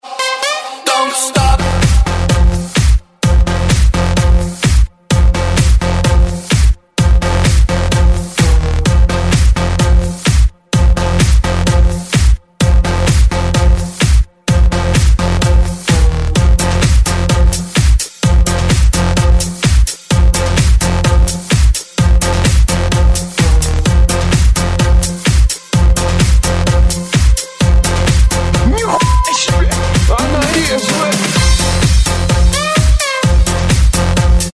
DJ版